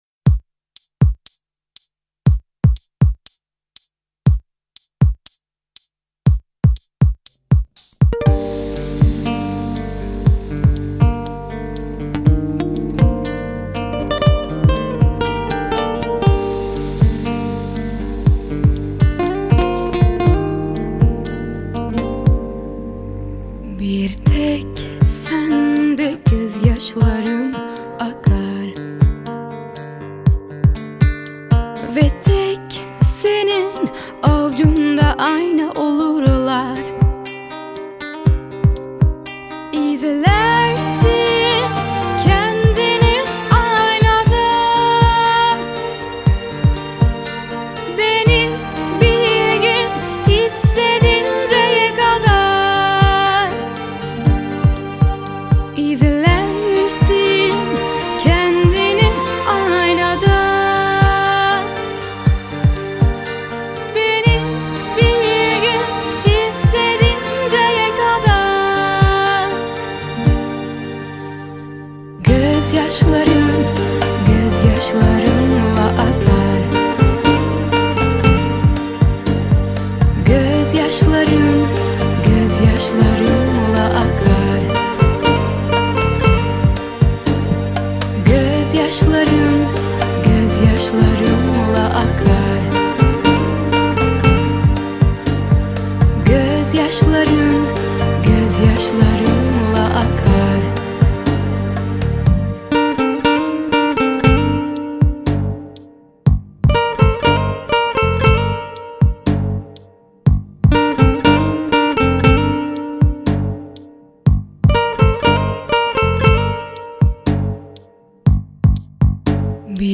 ریمیکس رسمی اهنگ
با صدای زن (ورژن آهسته)